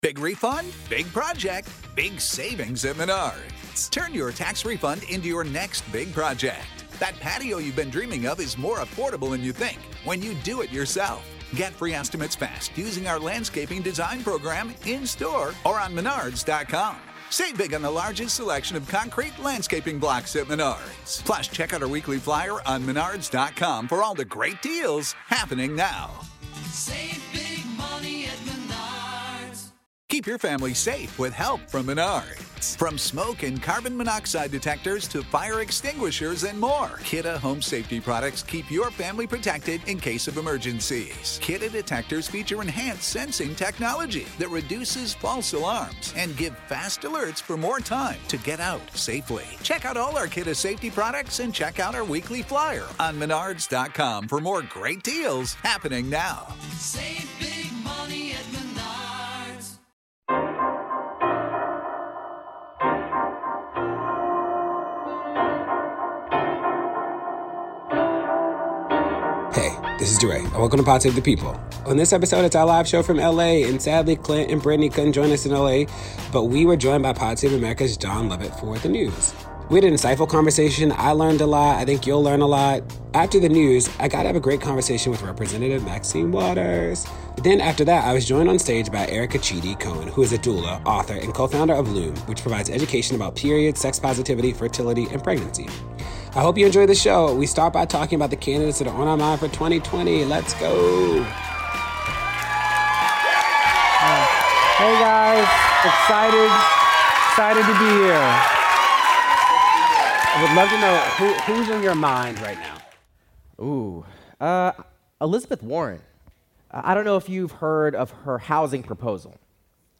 Rest Doesn’t Mean Failure (LIVE from Los Angeles, CA)